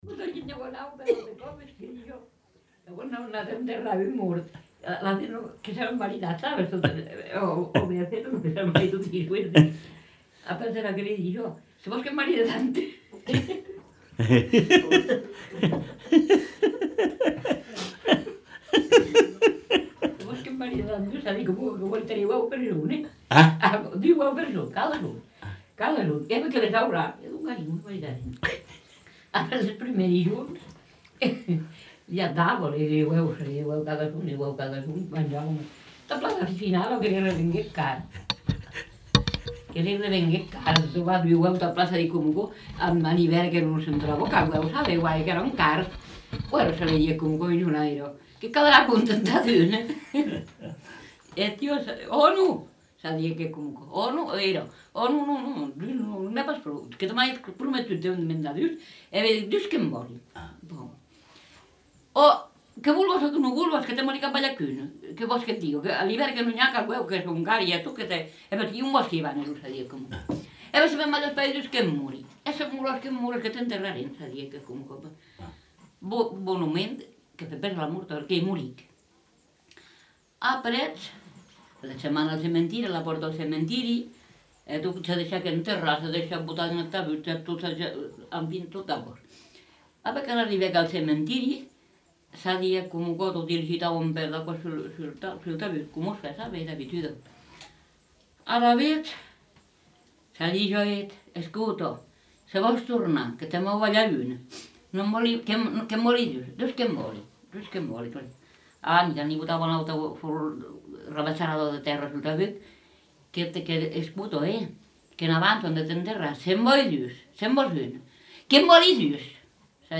Aire culturelle : Couserans
Genre : conte-légende-récit
Effectif : 1
Type de voix : voix de femme
Production du son : parlé
Classification : conte facétieux